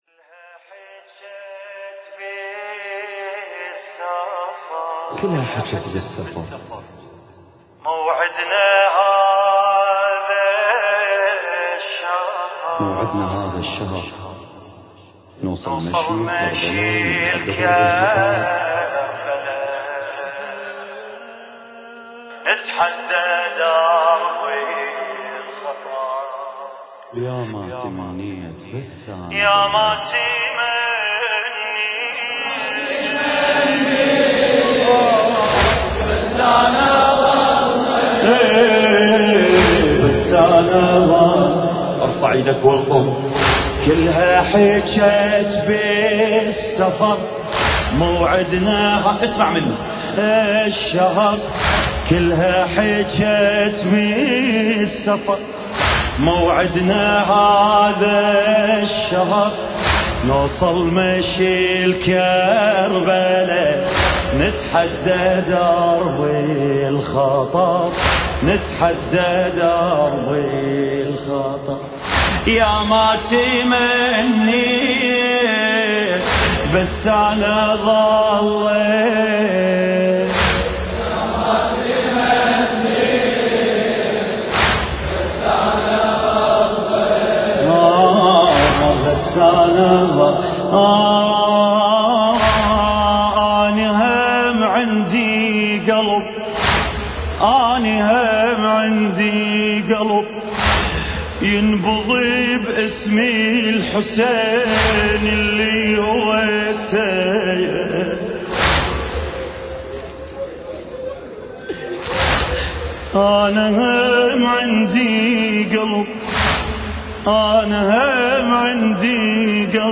إيران – المحمرة التاريخ